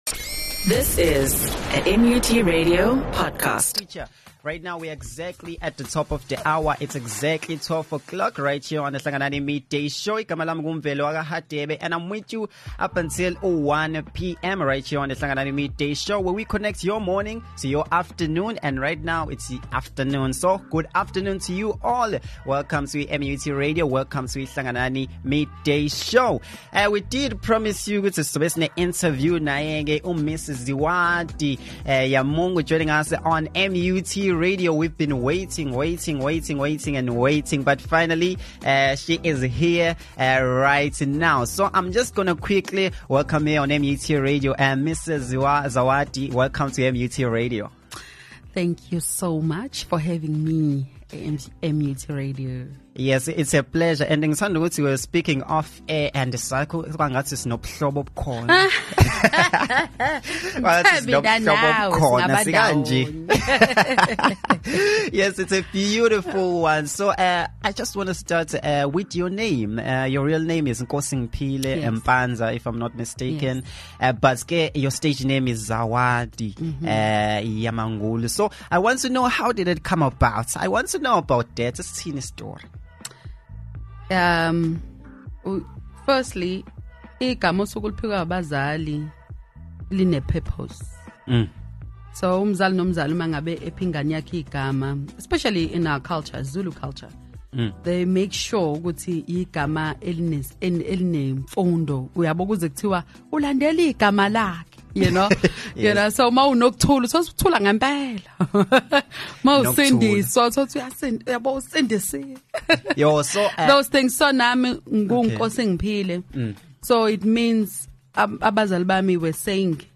The Hlanganani Midday Show